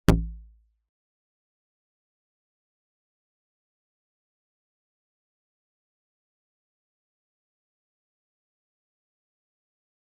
G_Kalimba-D1-mf.wav